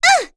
Pansirone-Vox_Damage_01.wav